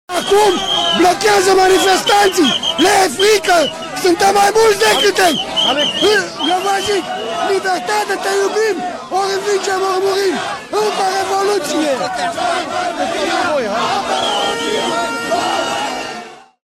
Ascultăm acum un mic fragment din timpul incidentelor:
20ian-19-Incidente-metrou.mp3